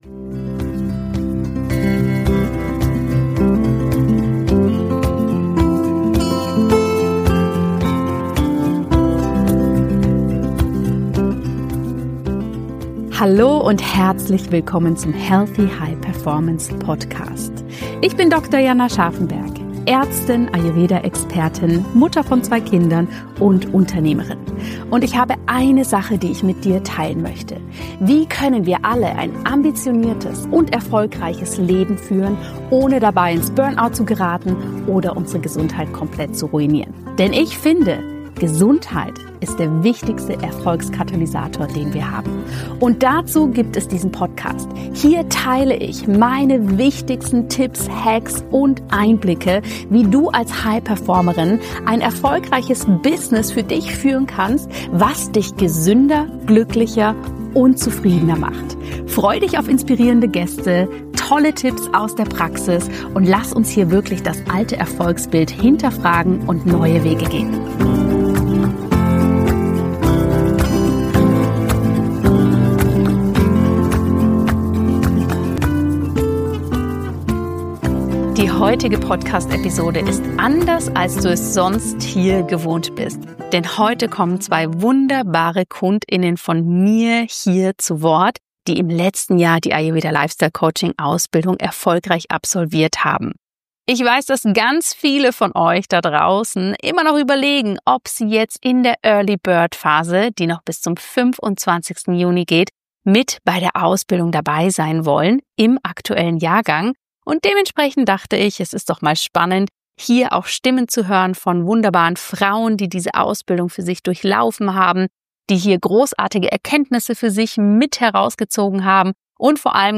Beschreibung vor 9 Monaten Podcast #368 - In dieser besonderen Podcast Folge kommen zwei Absolventinnen der Ayurveda Lifestyle Coaching Ausbildung zu Wort